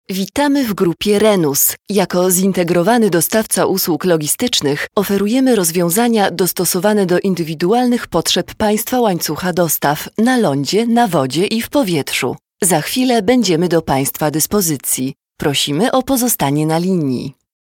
Narration 2